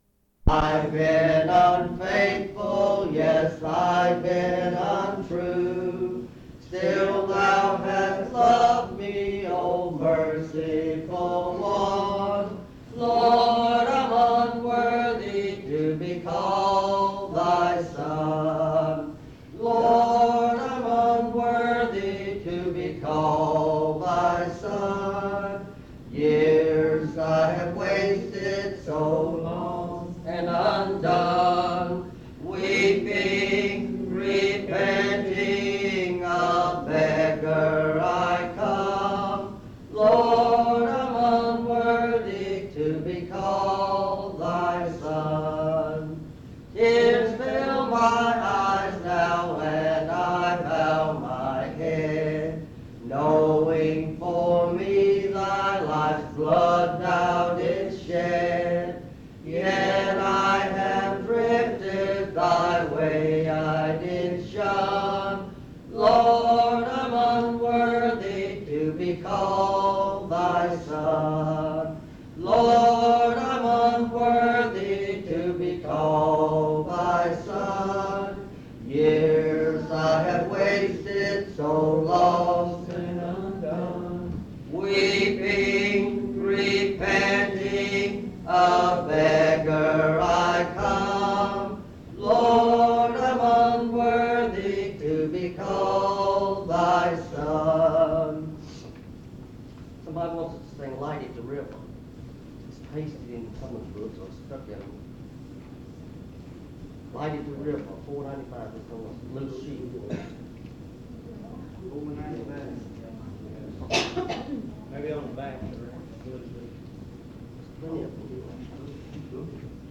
Message
at Monticello Primitive Baptist Church